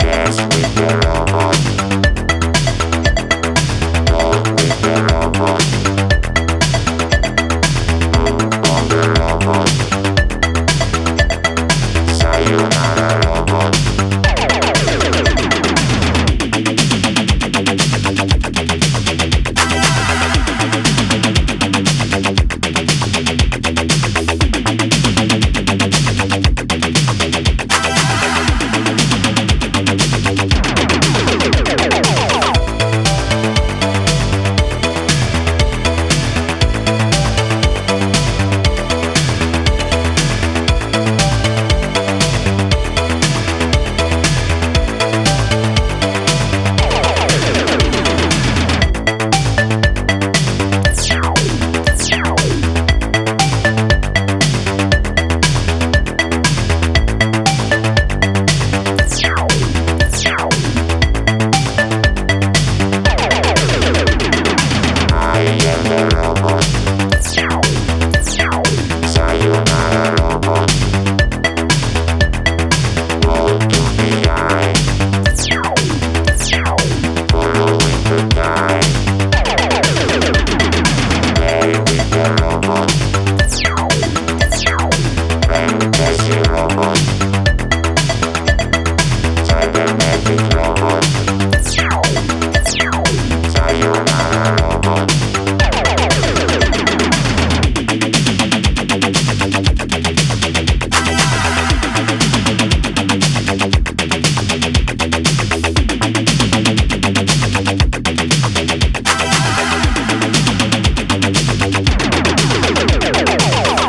2010年にリリースされていたとは俄には信じ難い、まんま1984年か1985年の音。